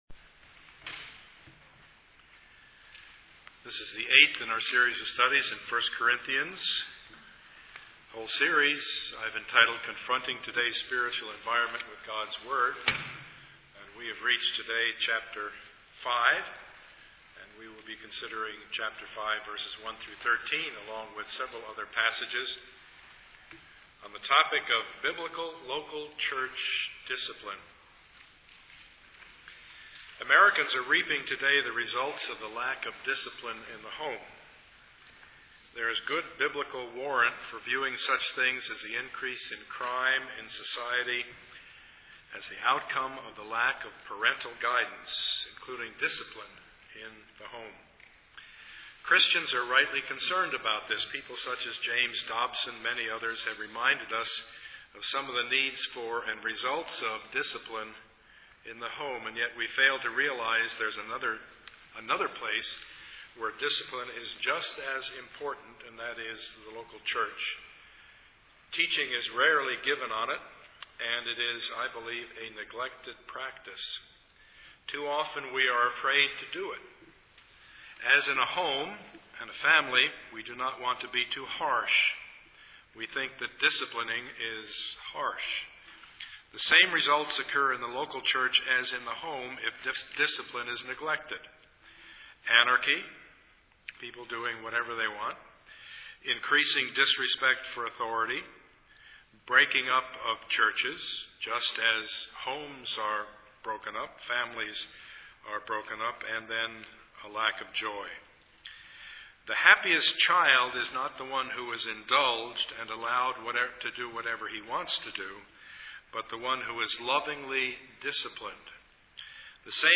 Passage: 1 Corinthians 5:1-13 Service Type: Sunday morning